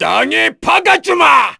Phillop-Vox_Skill5_kr.wav